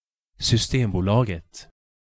Sv-systembolaget.ogg